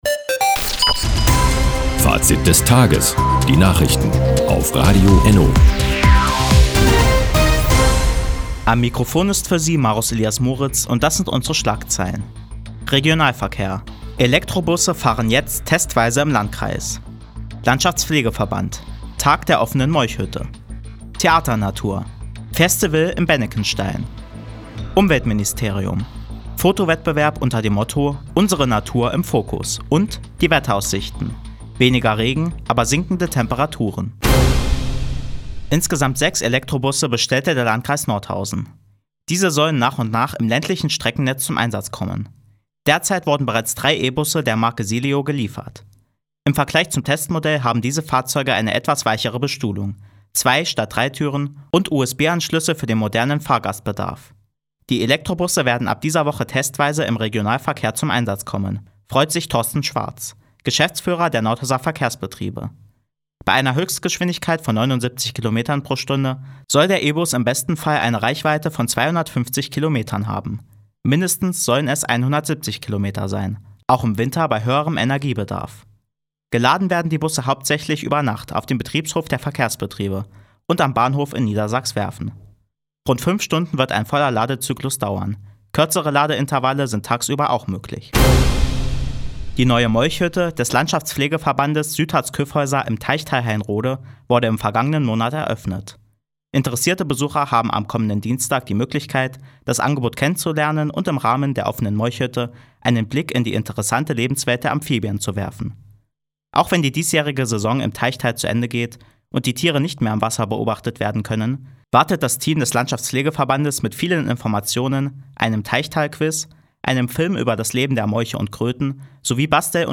Di, 17:05 Uhr 18.08.2020 Neues von Radio ENNO Fazit des Tages Anzeige symplr (1) Seit Jahren kooperieren die Nordthüringer Online-Zeitungen und das Nordhäuser Bürgerradio ENNO. Die tägliche Nachrichtensendung ist jetzt hier zu hören.